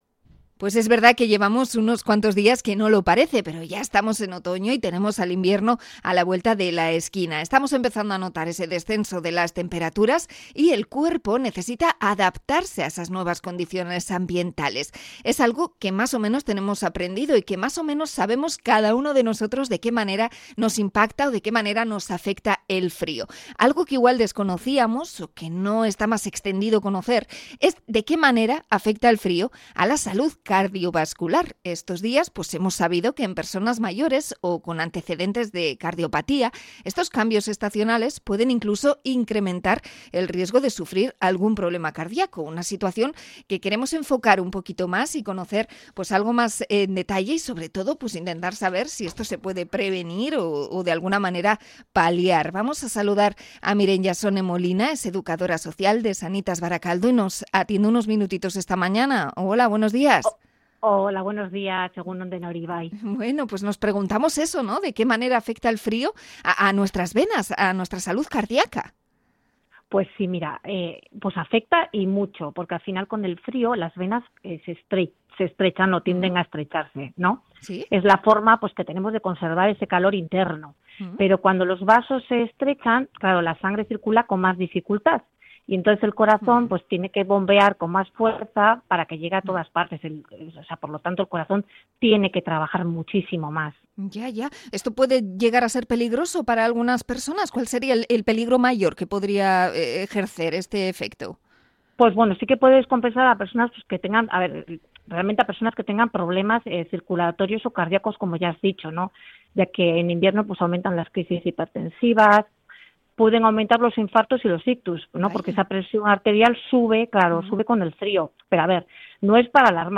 Entrevista a sanitaria sobre el frío y los problemas de corazón